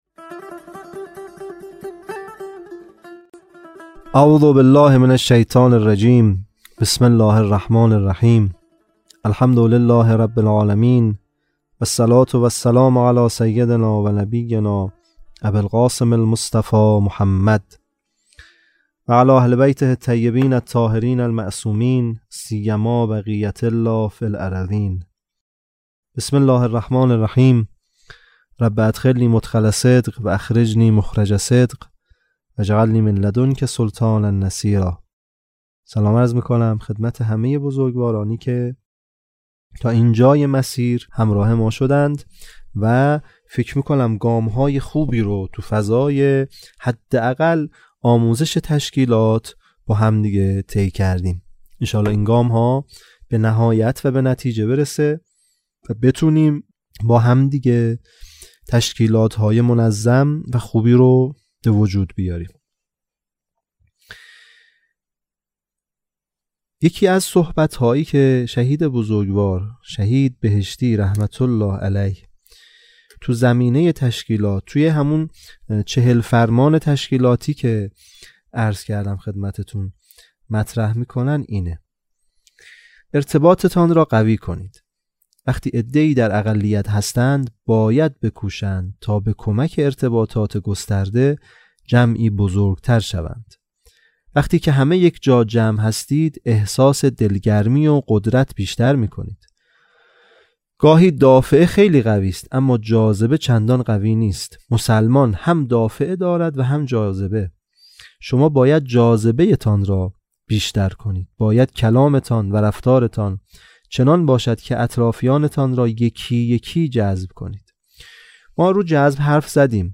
بیست و پنجمین جلسه «دوره آموزشی تشکیلات»